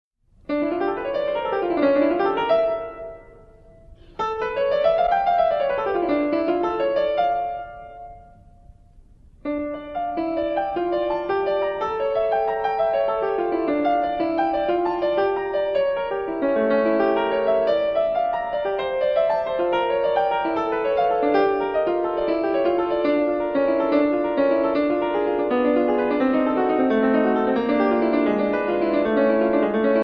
PRAGUE RECITAL (B?SENDORFER IMPERIAL)